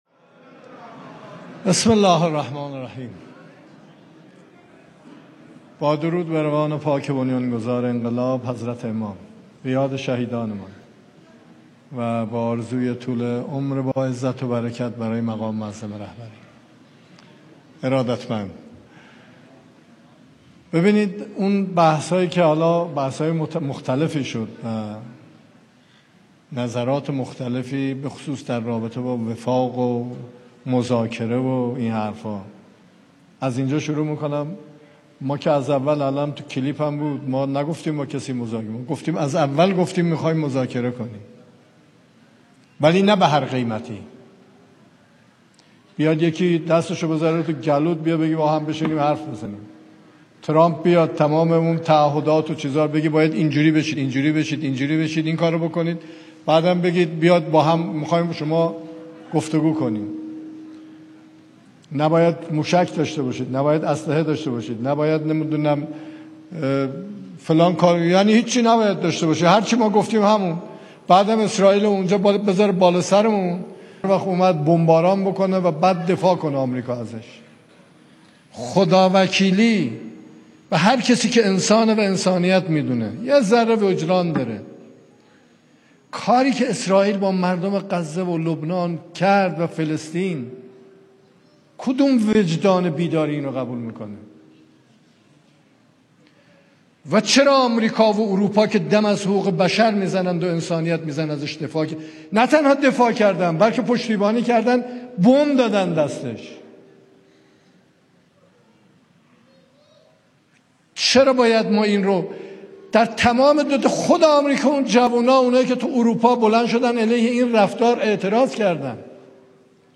سخنان رییس جمهور در جمع فرهیختگان و نخبگان غرب استان تهران